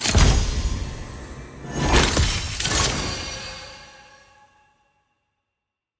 sfx-cs-aram-card-3-appear.ogg